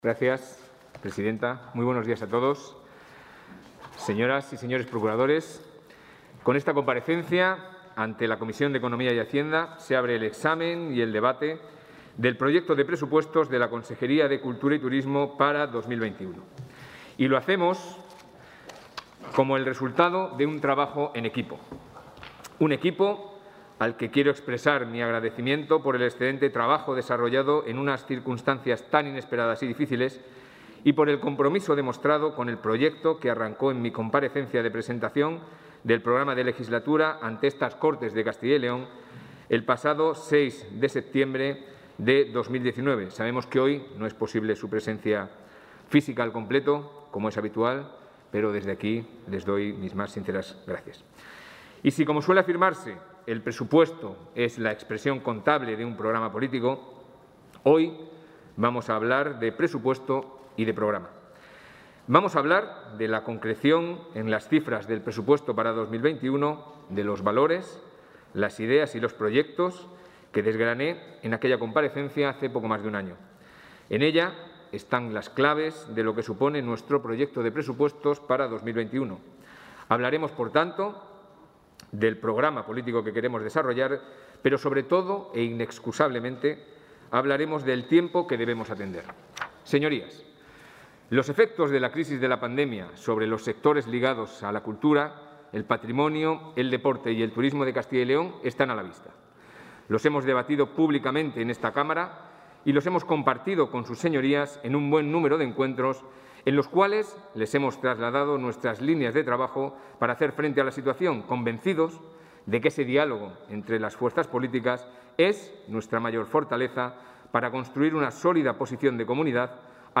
El consejero de Cultura y Turismo, Javier Ortega, ha presentado hoy en las Cortes de Castilla y León las partidas presupuestarias destinadas a...
Intervención del consejero de Cultura y Turismo.